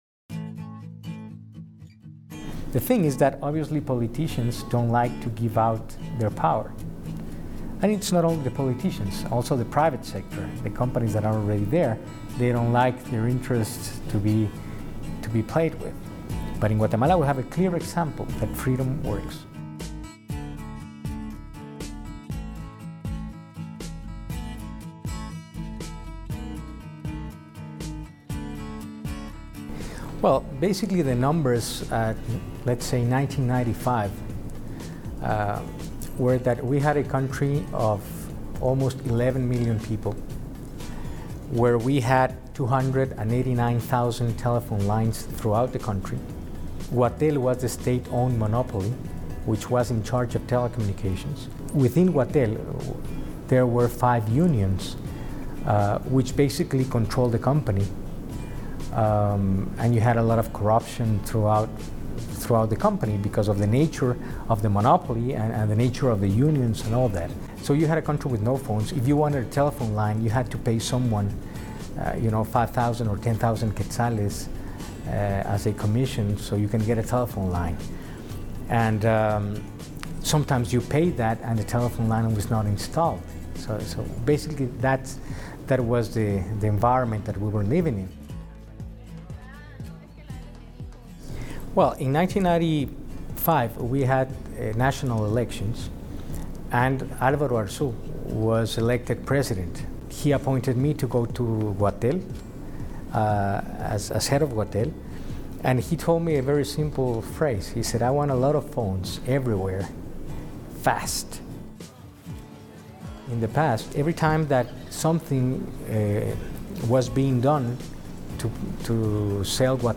The Guatemalan Telecommunications Miracle: A Conversation